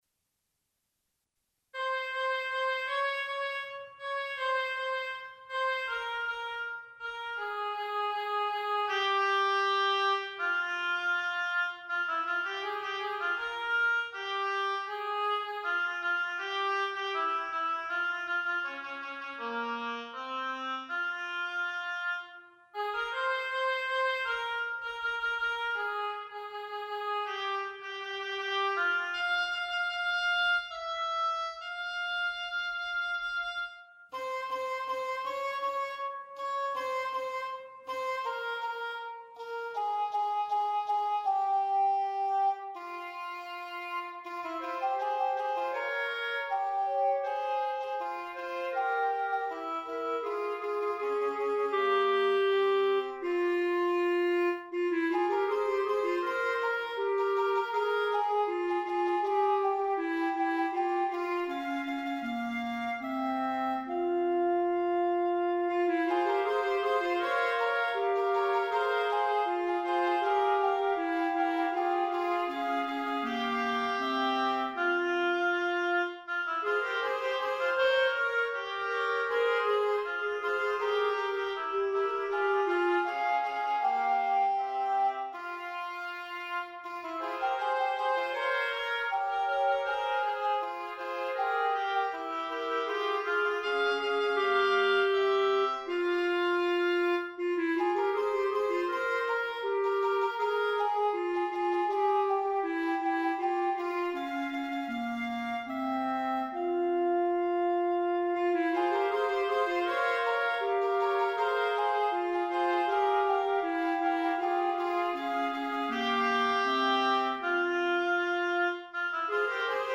canon à 3 voix, en si bémol majeur.